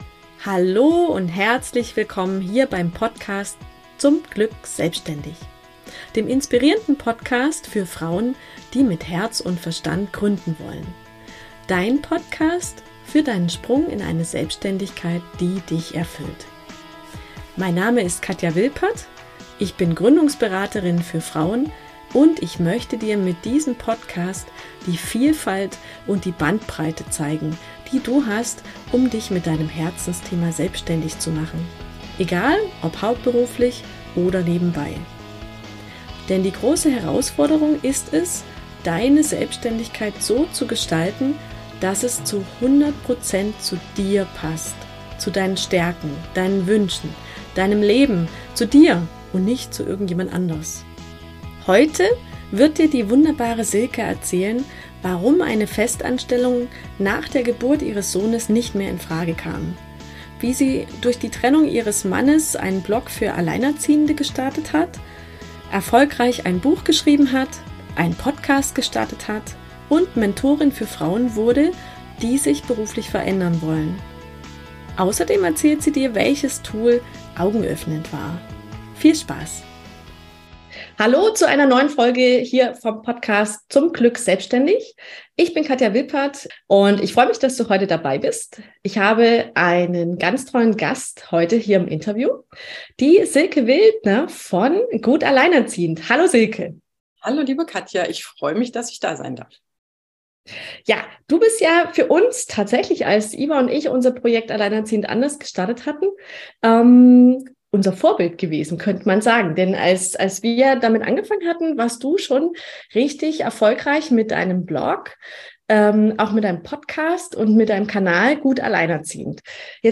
Viel Spaß im Interview mit ihr!